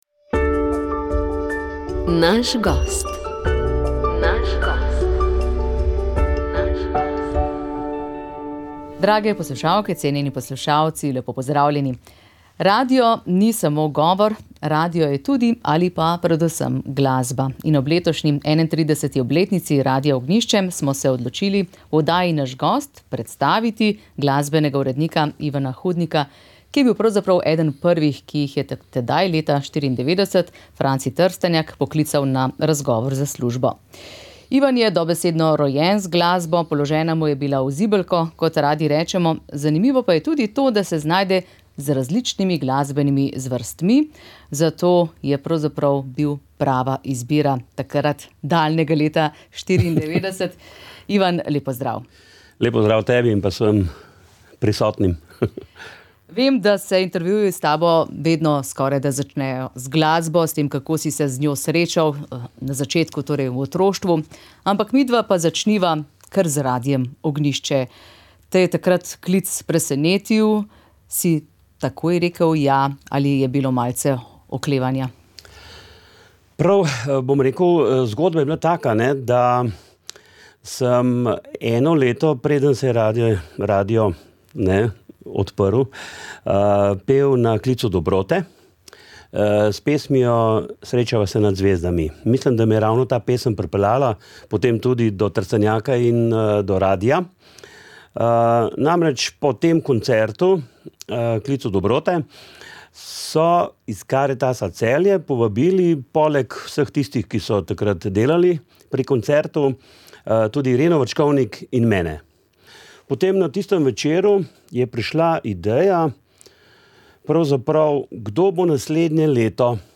V torek 2. decembra obeležujemo Mednarodni dan boja proti suženjstvu. V tej luči smo z gosti govorili o položaju v Sloveniji, številkah s tega področja in zakonskih pomanjkljivostih ter mogočih rešitvah.